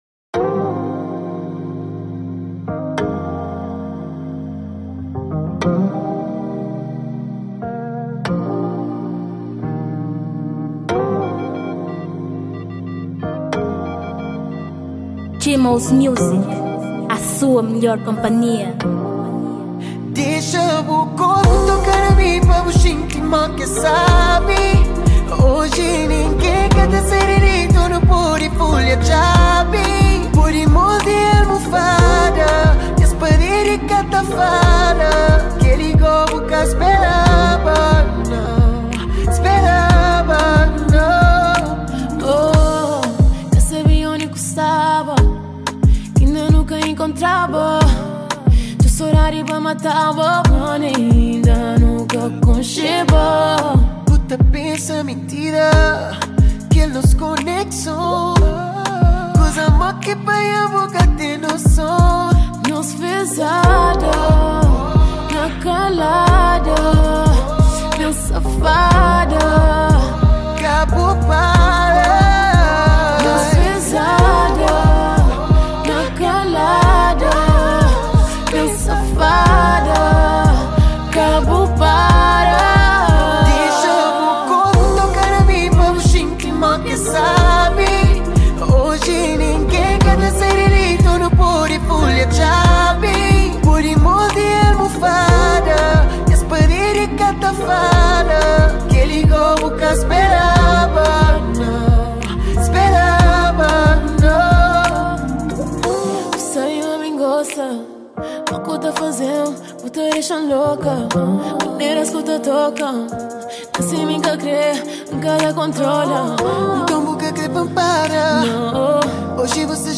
2023 Gênero: Zouk Tamanho